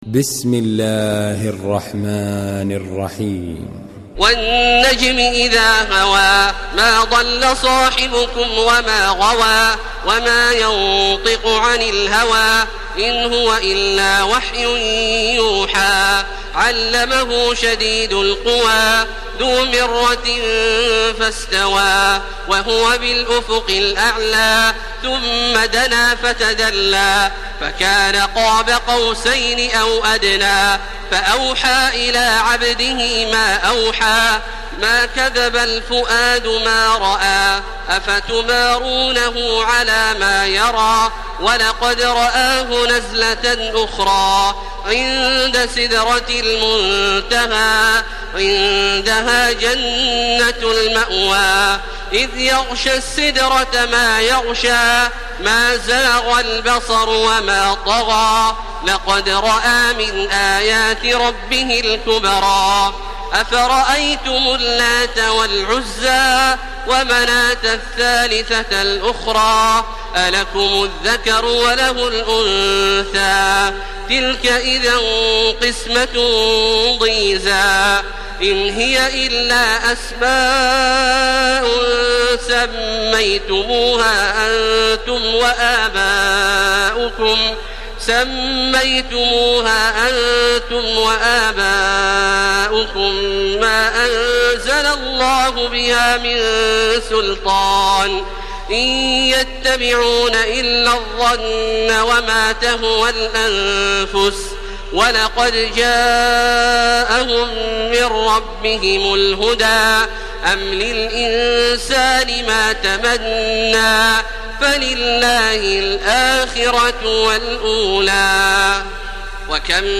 تحميل سورة النجم بصوت تراويح الحرم المكي 1431
مرتل